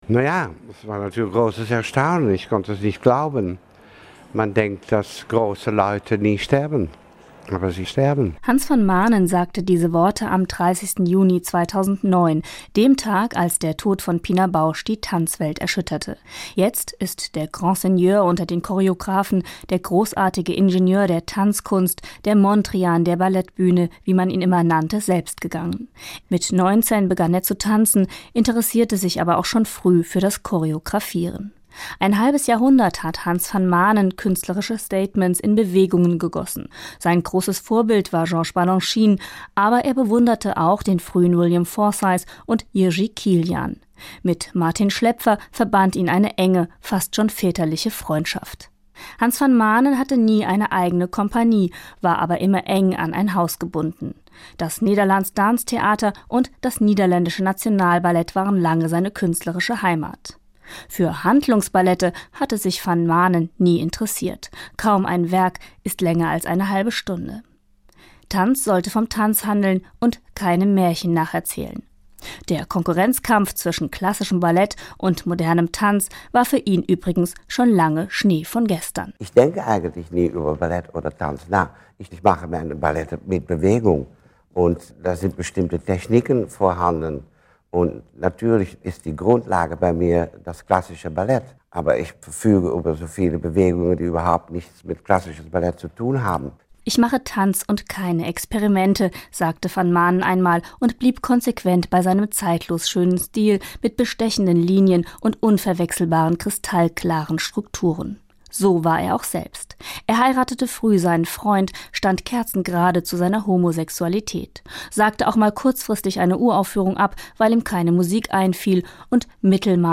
nachruf-hans-van-manen.mp3